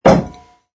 sfx_put_down_bottle02.wav